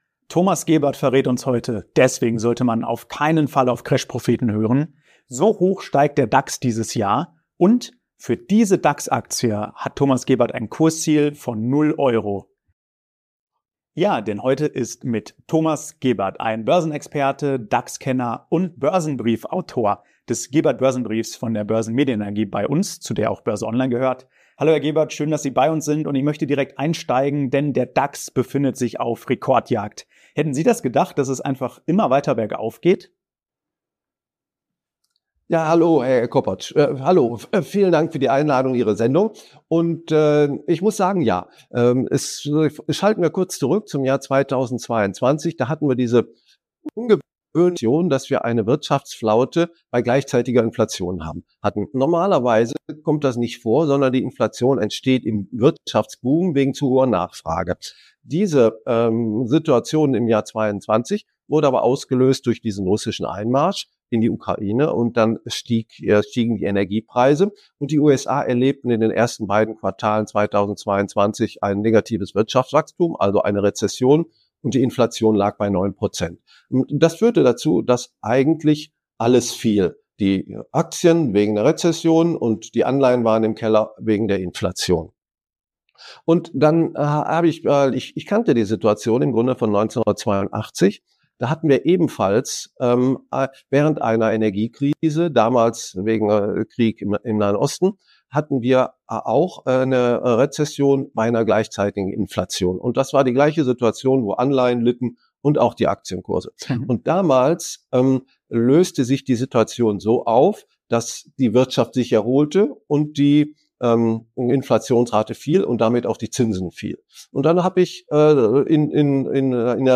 BÖRSE ONLINE im Talk mit Finanzexperte